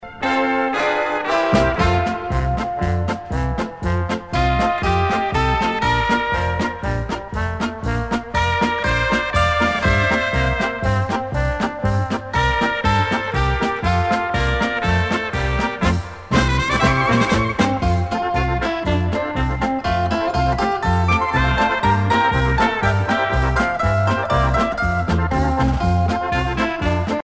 danse : marche-polka
Pièce musicale éditée